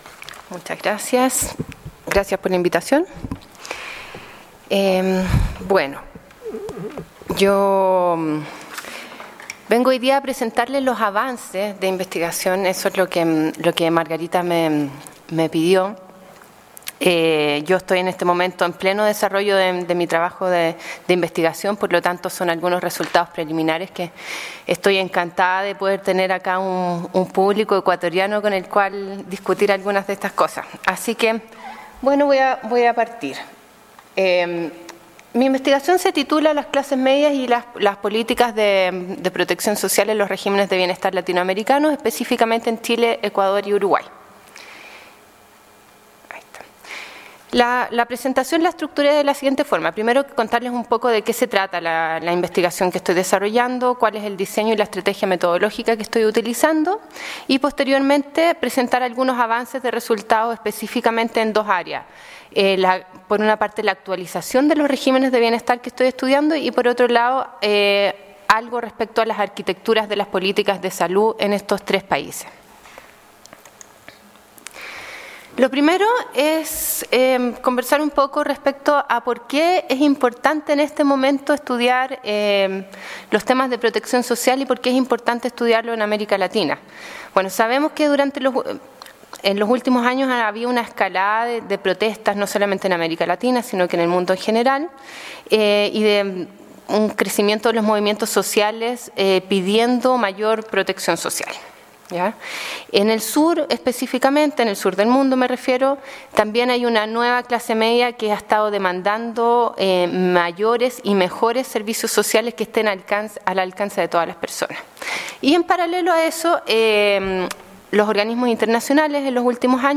FLACSO Ecuador, su Maestría de Investigación en Políticas Públicas, y el grupo de investigación ‘Acción pública y políticas sociales’ del Departamento de Asuntos Públicos organizó el Coloquio, Derechos sociales y políticas públicas en clave comparada.